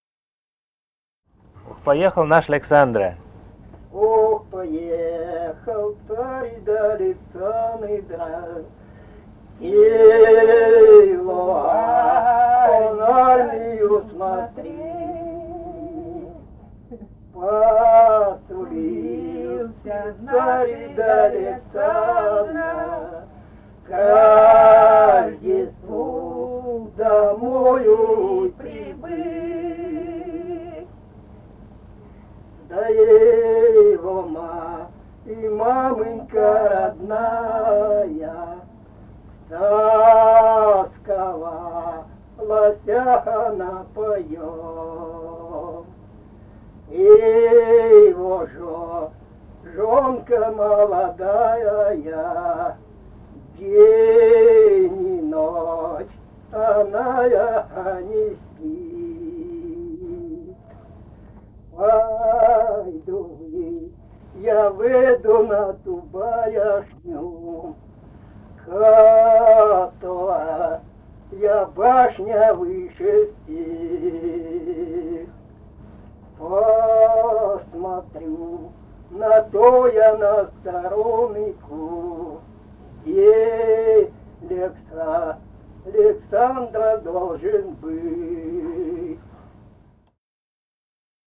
Этномузыкологические исследования и полевые материалы
Алтайский край, Заимка Борзовая (округ г. Барнаула), 1967 г. И1016-12